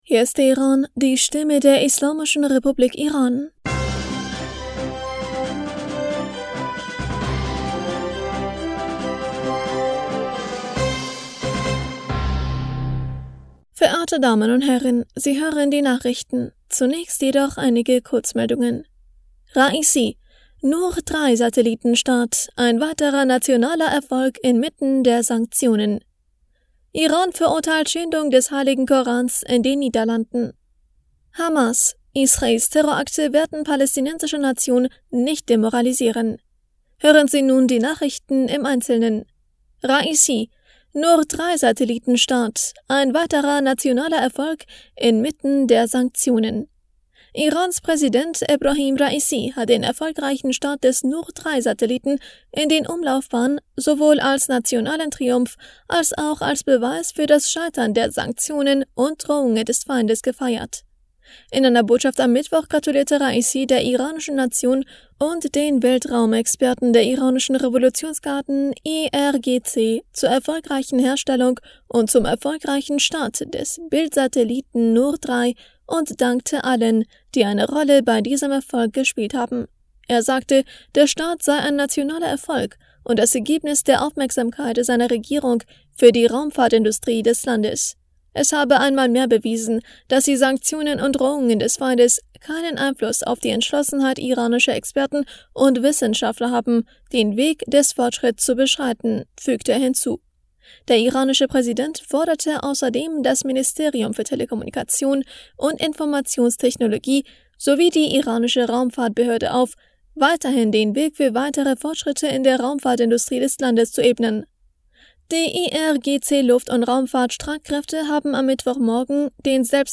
Nachrichten vom 28. September 2023